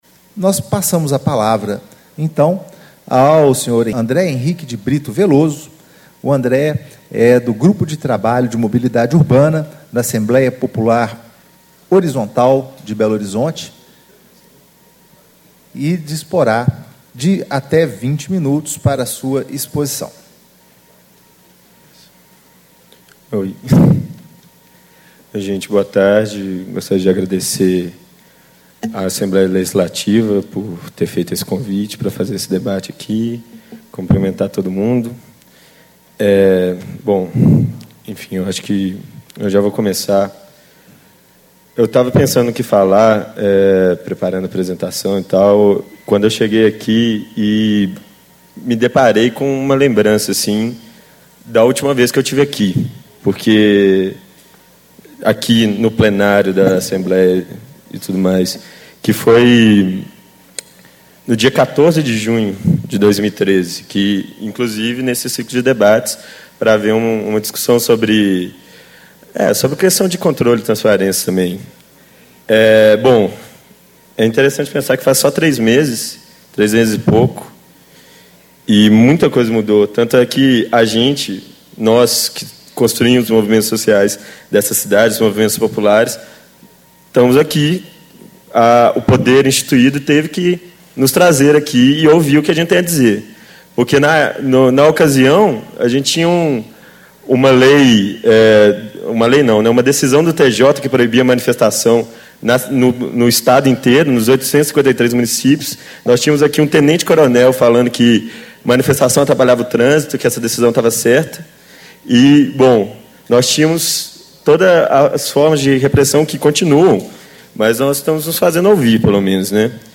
Painel: Transporte Coletivo: Tarifas, Gratuidades e Transparência
Encontro Estadual do Fórum Técnico Mobilidade Urbana - Construindo Cidades Inteligentes
Discursos e Palestras